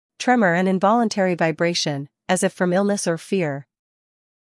英音/ ˈtremə(r) / 美音/ ˈtremər /